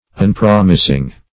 unpromising - definition of unpromising - synonyms, pronunciation, spelling from Free Dictionary